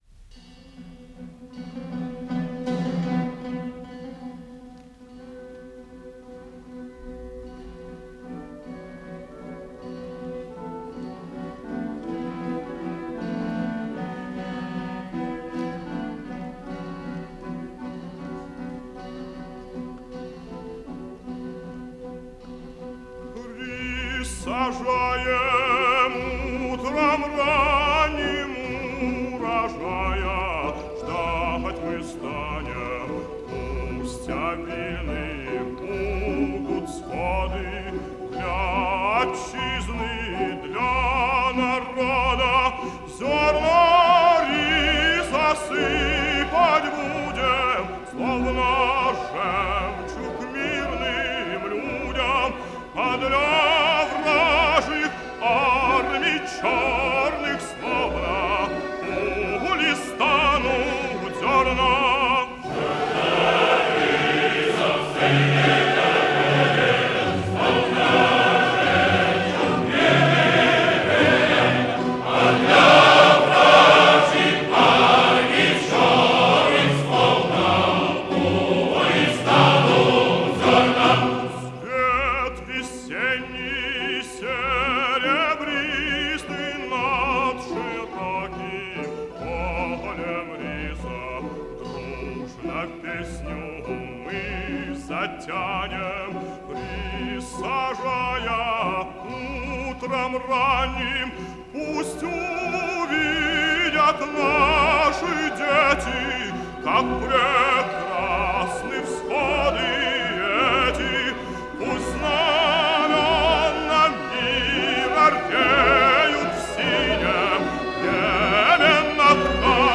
Описание: корейская песня